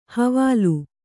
♪ havālu